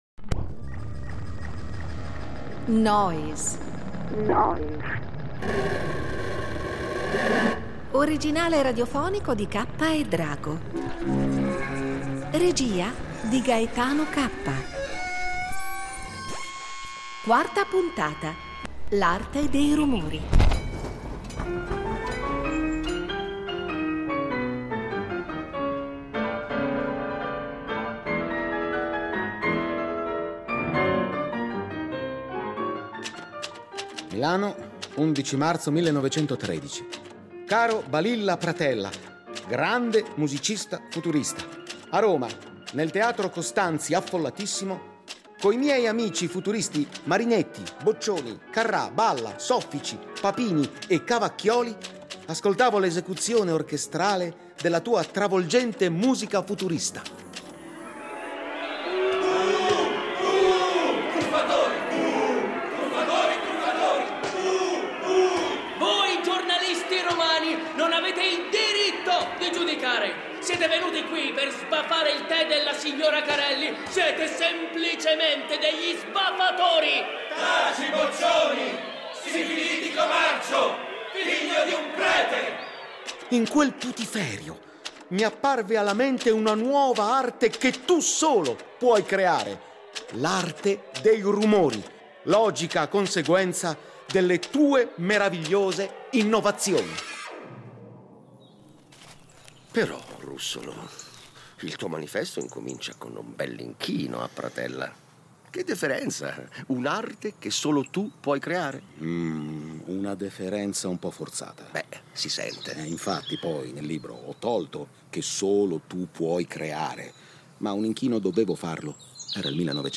Nel radiodramma abbiamo però privilegiato il Russolo musicista, compositore e inventore di strumenti sonori.